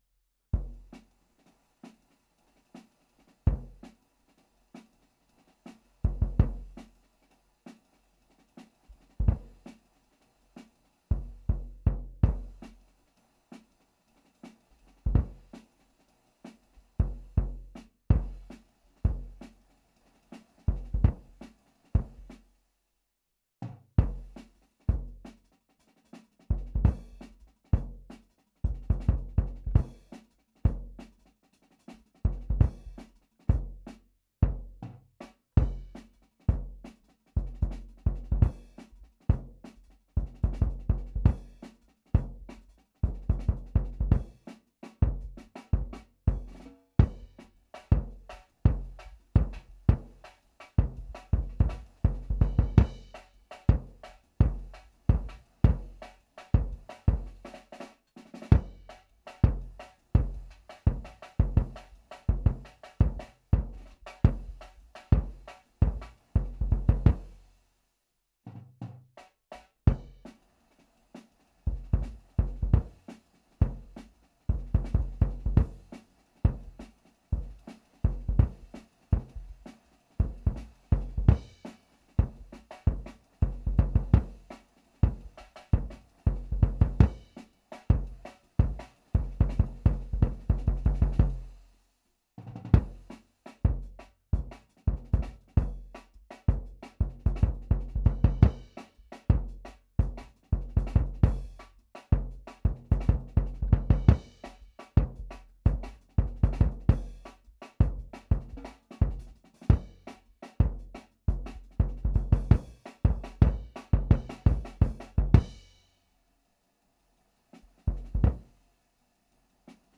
Index of /4 DRUM N BASS:JUNGLE BEATS/BEATS OF THE JUNGLE THAT ARE ANTIFUNGAL!!/RAW MULTITRACKS
KICK PUNCH_1.wav